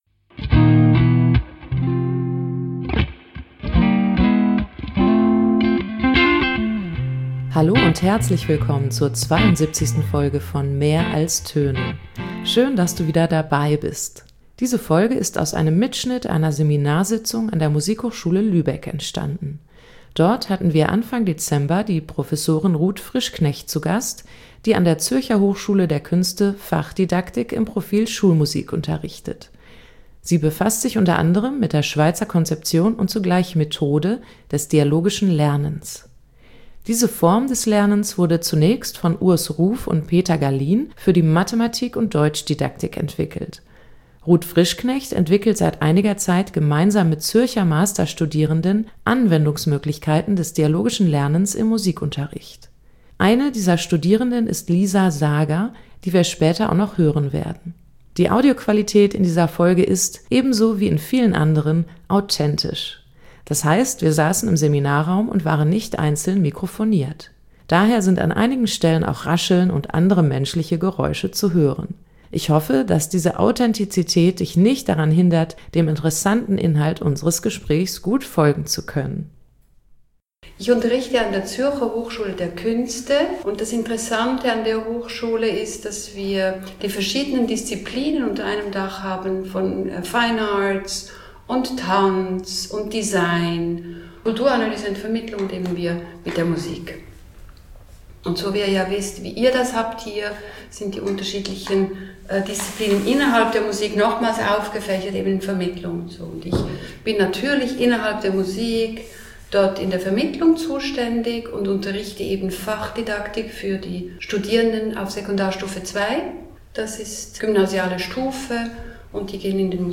Diese Folge gibt einen Einblick in ein Musikdidaktik-Seminar an der Musikhochschule Lübeck